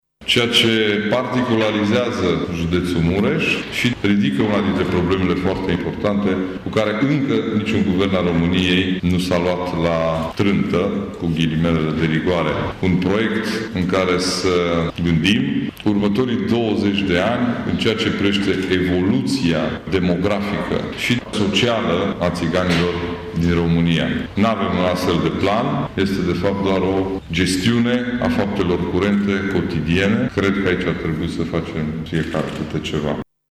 Este remarca făcută, astăzi, de președintele CJ Mureș, Ciprian Dobre, cu prilejul vizitei de studii şi documentare in judet a cursanţilor seriei a XXVI-a a Colegiului Naţional de Apărare.